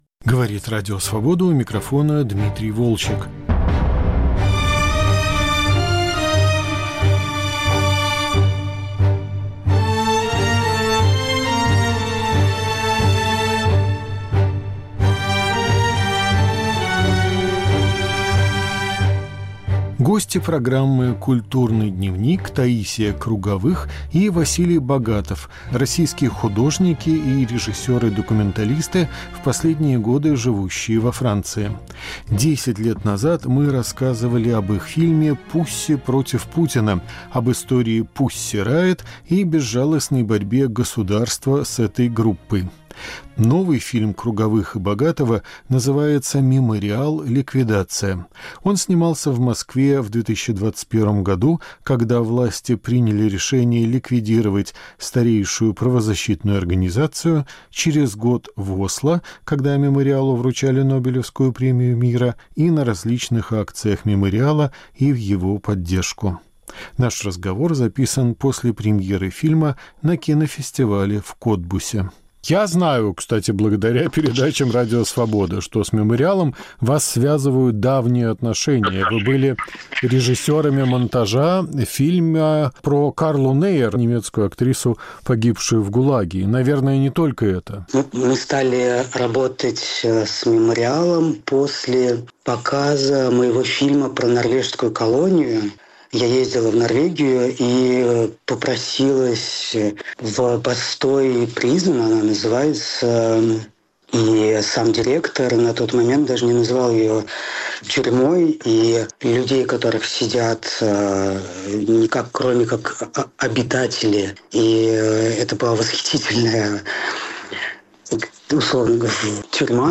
Разговор с документалистами после премьеры фильма «Мемориал/Ликвидация»